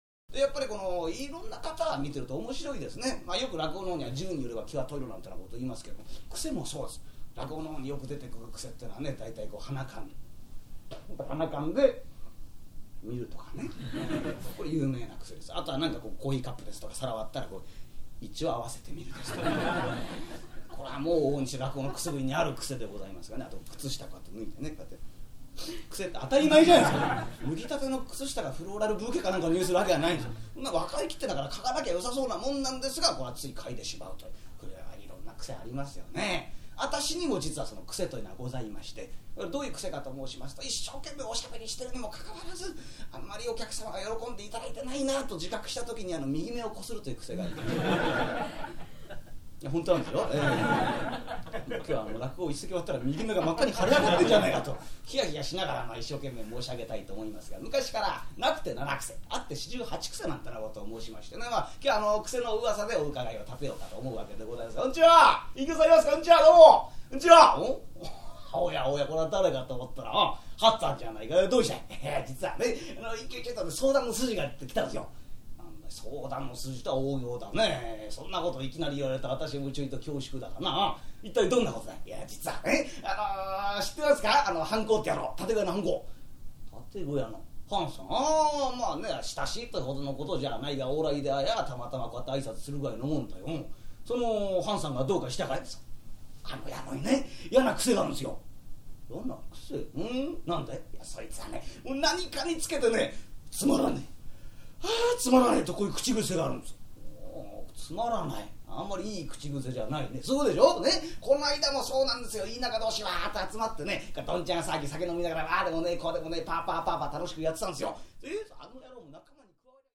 馬鹿馬鹿しい遊びに全身全霊をかたむける、屈託のない江戸っ子の様子が楽しい一席です。